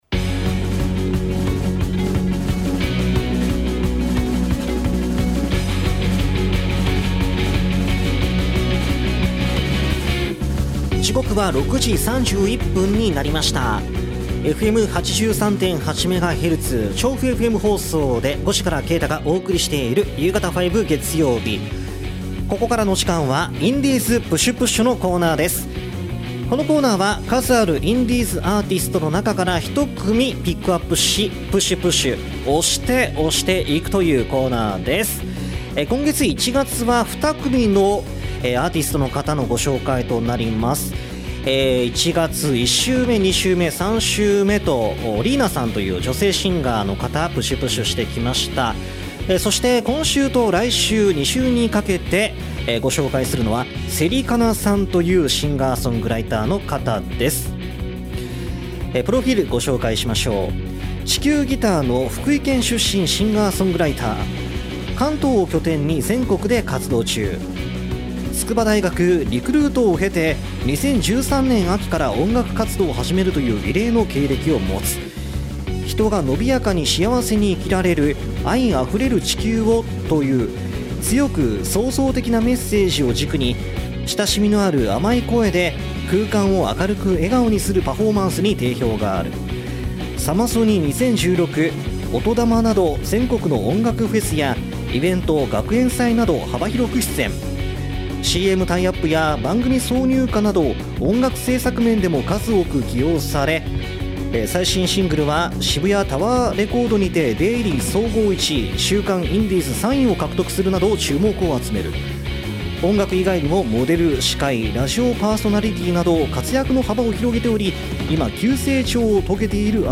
今回の放送同録音源はこちら↓